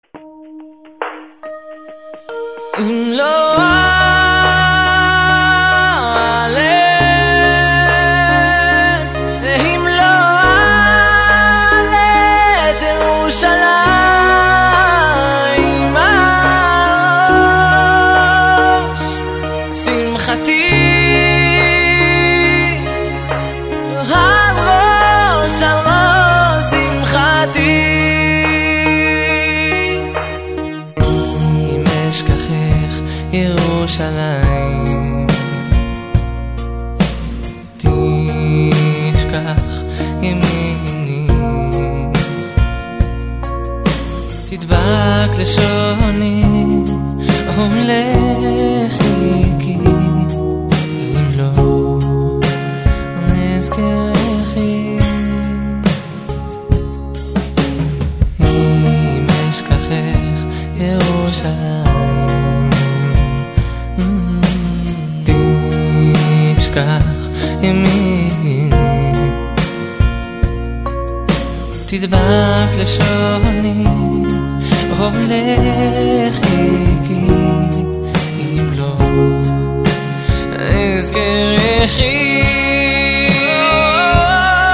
• Качество: 256, Stereo
поп
Еврейские